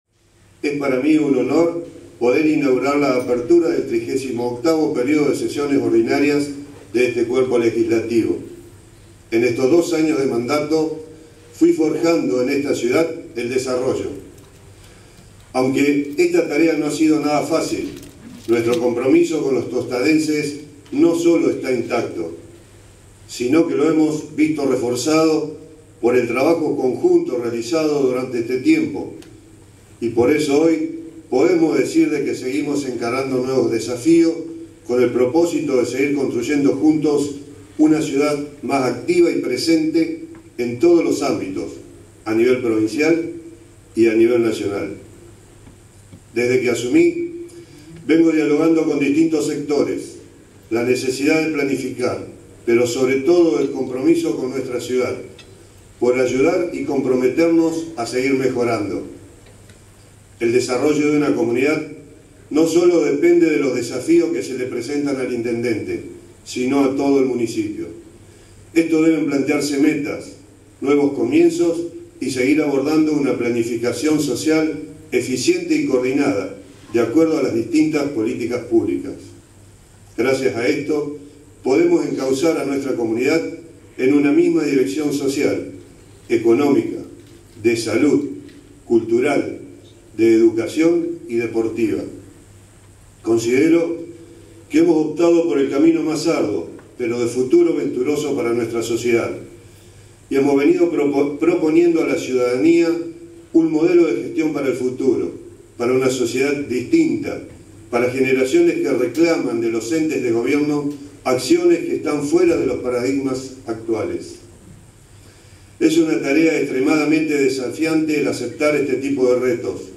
Este lunes a la noche se realizó en Tostado el acto correspondiente a la Trigésima Octava Apertura Anual del Concejo Deliberante, donde estuvieron presentes autoridades, representantes de instituciones y vecinos.
La actividad tuvo como punto central el mensaje del intendente Enrique Mualem, que repasó el trabajo que viene llevando adelante la Municipalidad, coordinando acciones con el cuerpo legislativo de la ciudad.
Además, el presidente del Concejo, Gerardo Bertolino, también se dirigió a los presentes.